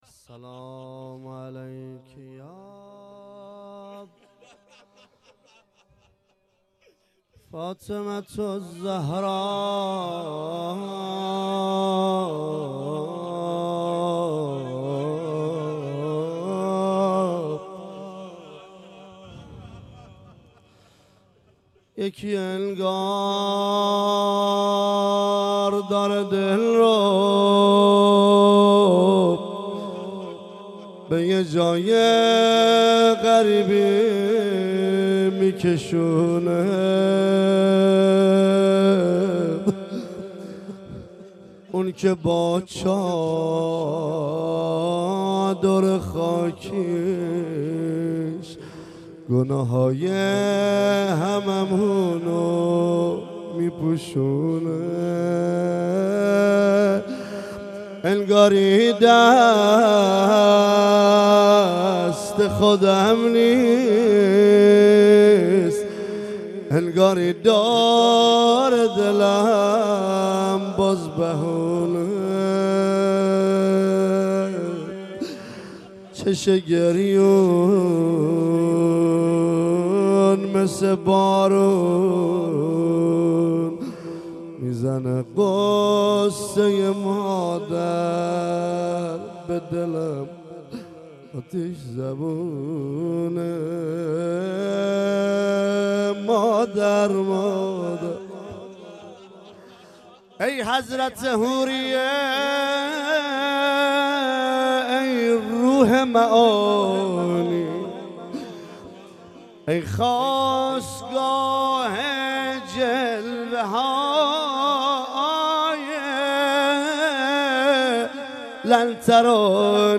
روضه مداحی